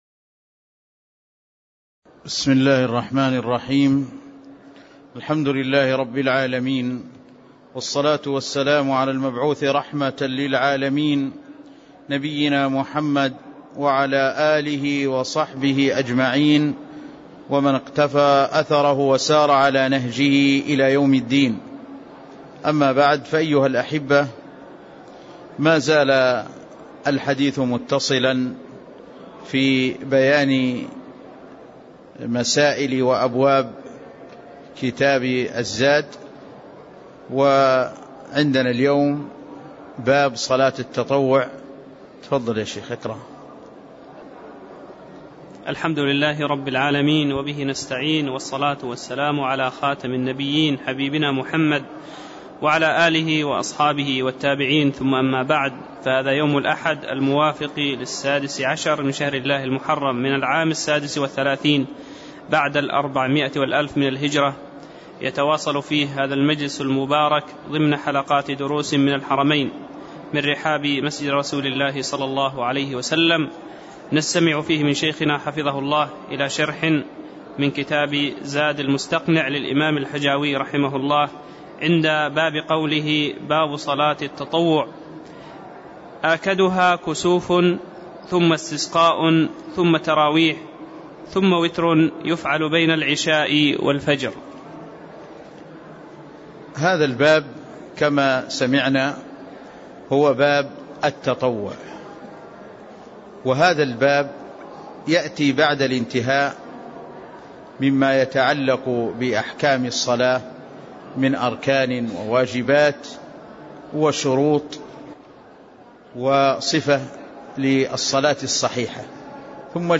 تاريخ النشر ١٦ محرم ١٤٣٦ هـ المكان: المسجد النبوي الشيخ